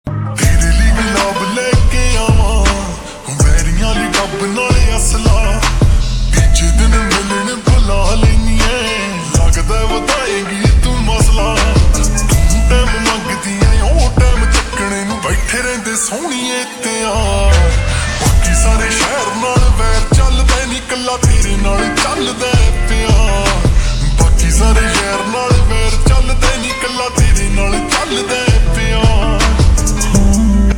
Punjabi Songs
Slow Reverb Version
• Simple and Lofi sound
• Crisp and clear sound